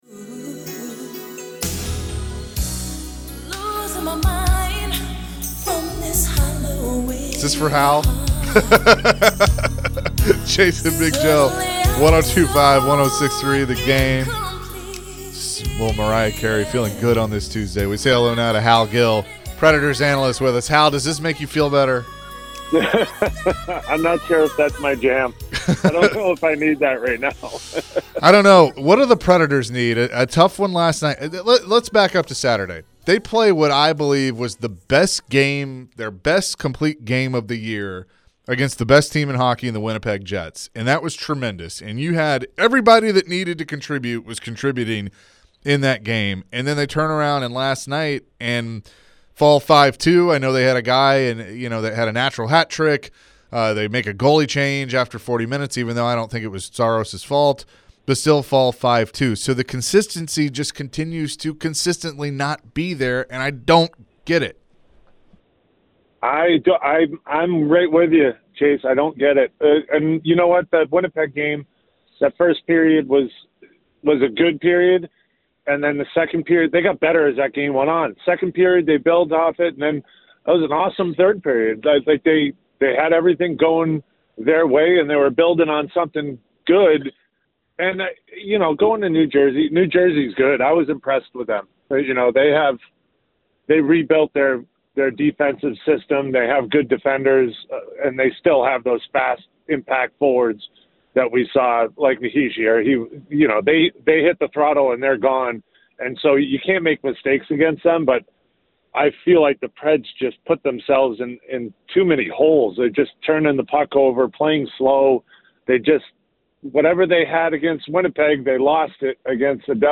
Preds analyst Hal Gill joined the show to share his thoughts on the Predators' current issues. Why has the season been so disappointing despite their big expectations?